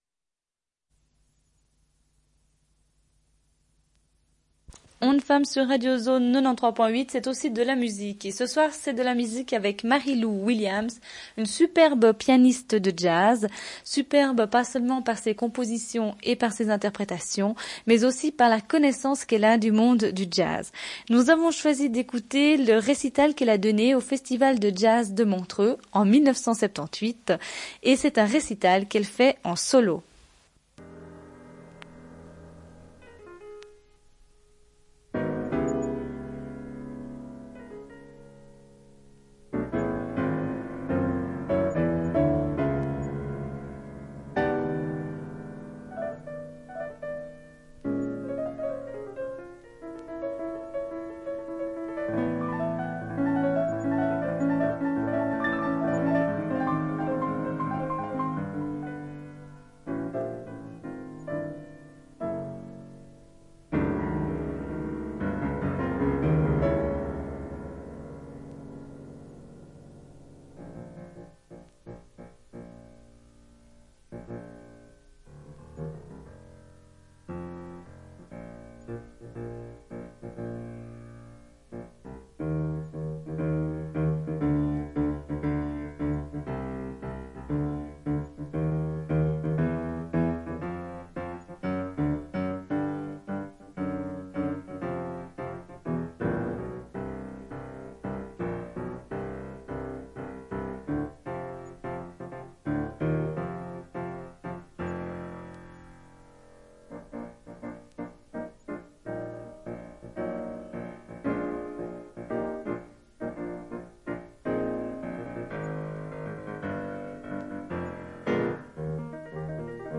Émission « magazine » avant l'été. Musiques, conseils de lecture de plage et téléphones. Première partie de l'émission consacrée à Marie-Lou Williams, jazz-woman.